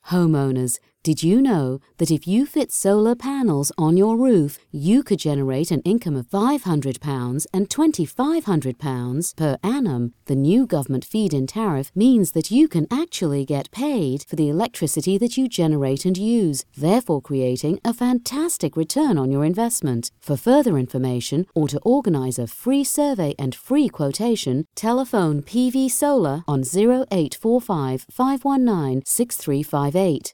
Voice over talent English (British), native speaker. Nonaccent voice. International global vibe.
Sprechprobe: Werbung (Muttersprache):